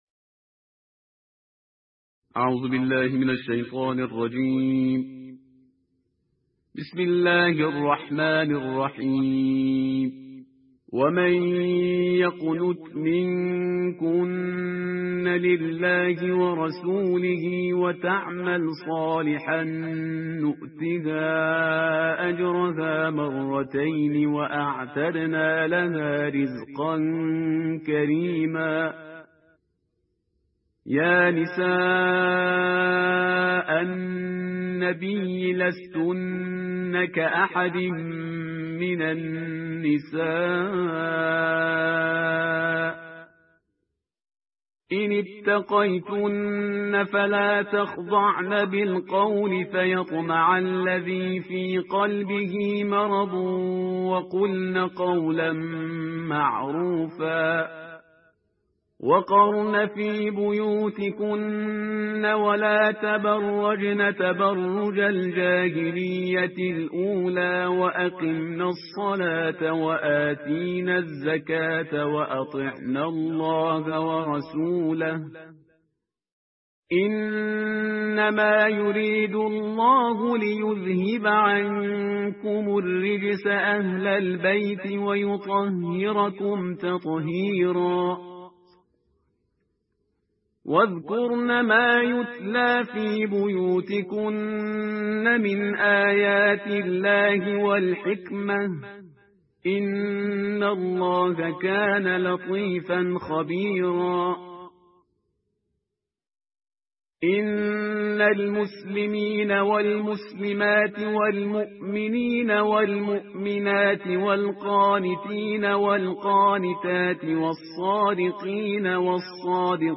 صوت/ ترتیل جزء بیست و دوم قرآن توسط "پرهیزگار"
قرائت قرآن